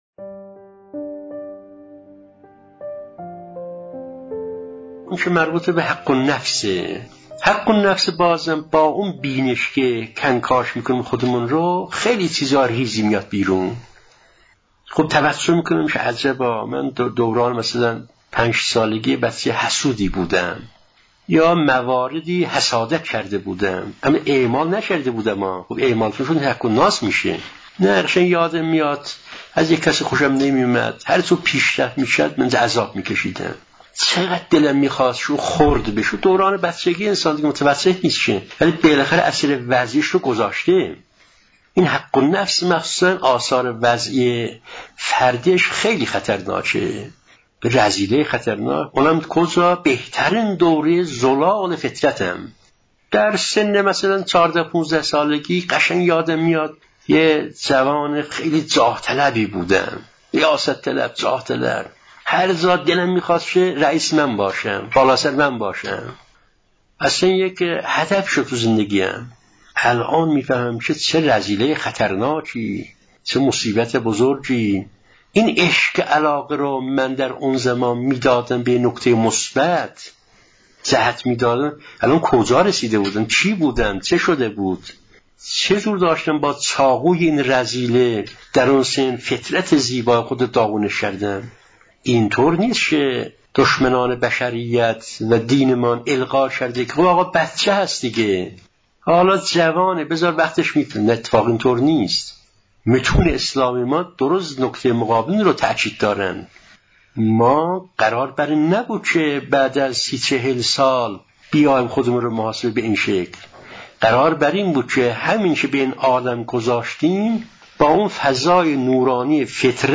صوت بیانات معرفتی